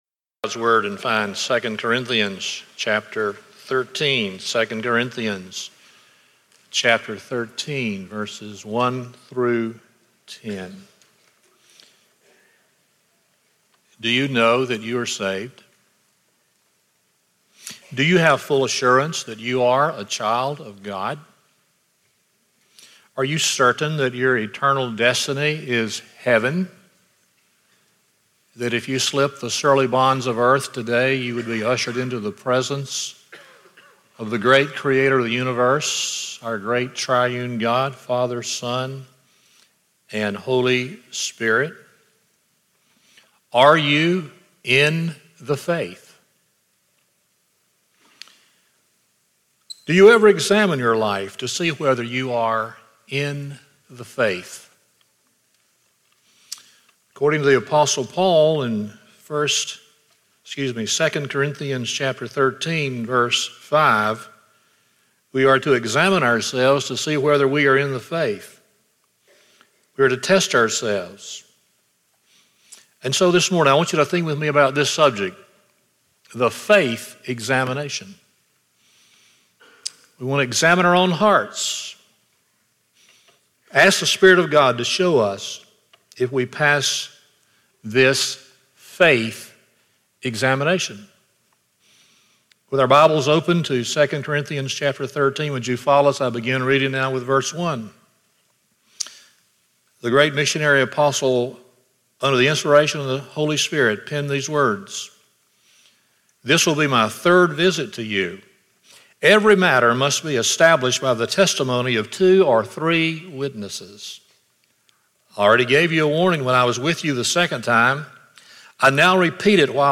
2 Corinthians 13:1-10 Service Type: Sunday Morning 1.